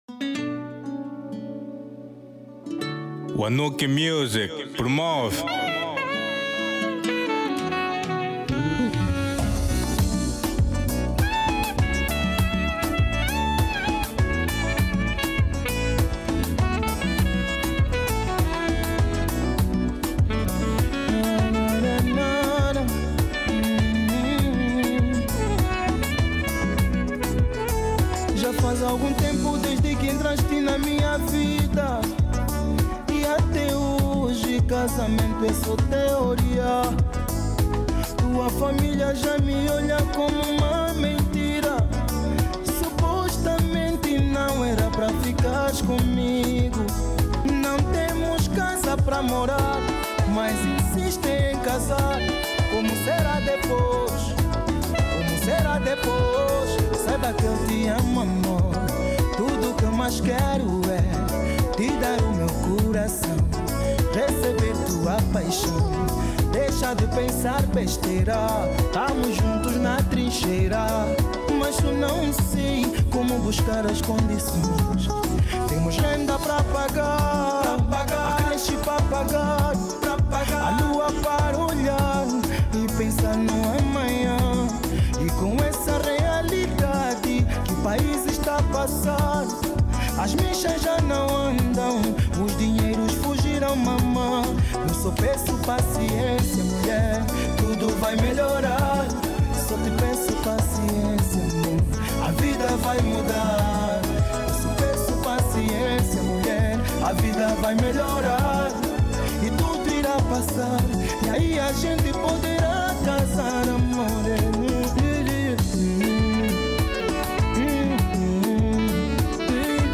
Genero: Semba